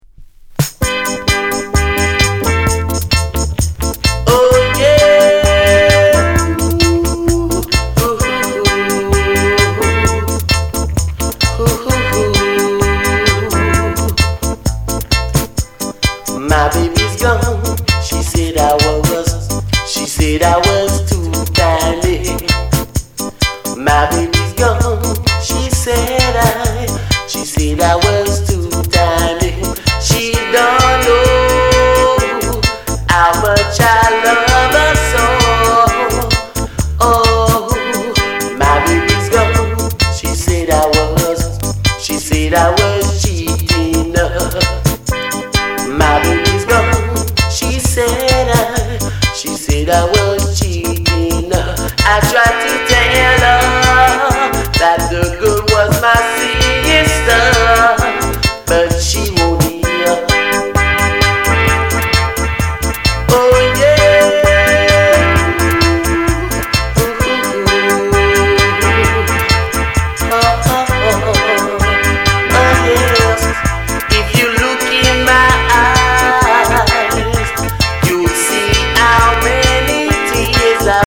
Genre: Reggae/Lovers Rock